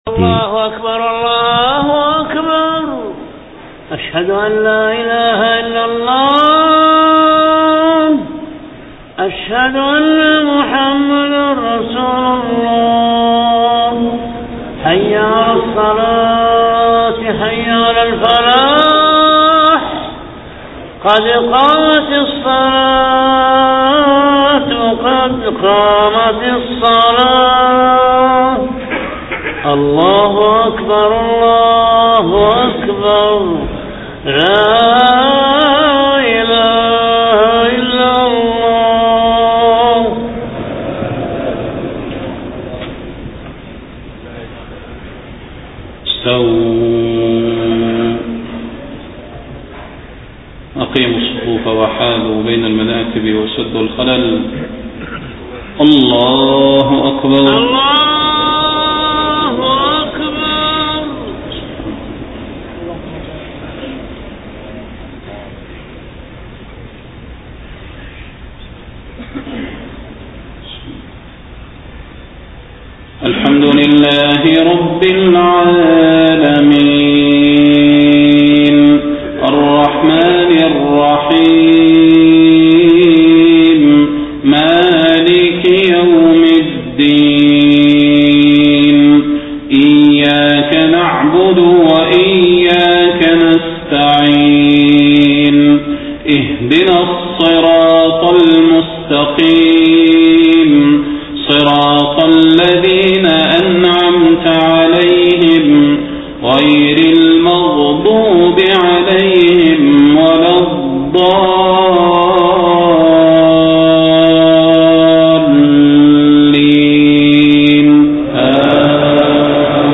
صلاة المغرب 9 ربيع الأول 1431هـ سورة البروج كاملة > 1431 🕌 > الفروض - تلاوات الحرمين